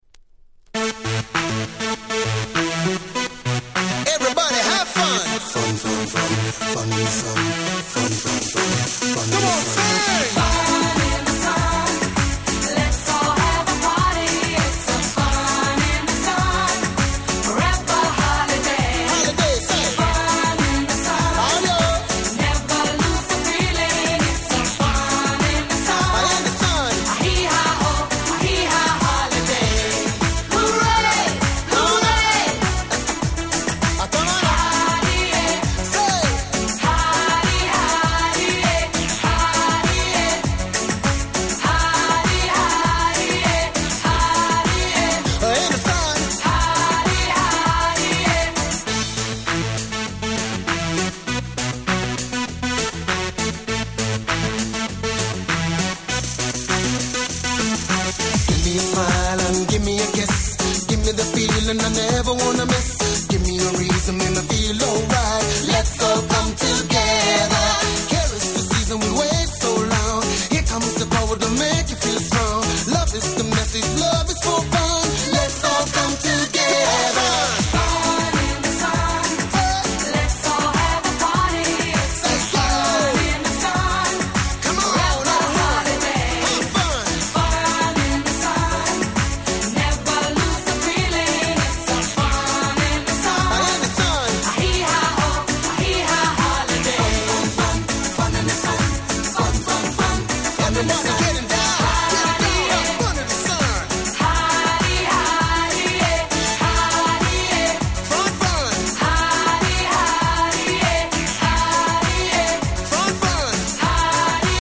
キャッチーDance Pop人気曲